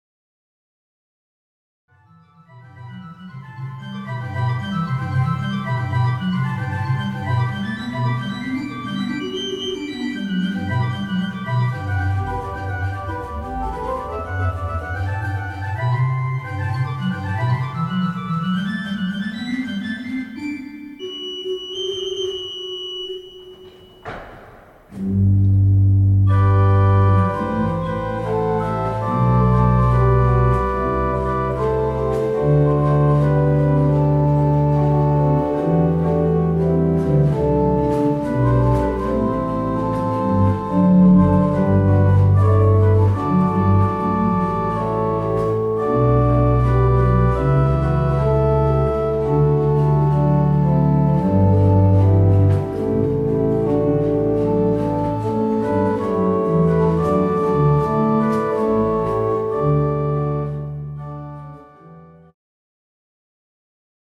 Kirkon urut rakensi Gustav Normann vuonna 1848.
Normann-urkujen loistosta
ja soinnikkaasta yhteisnumerosta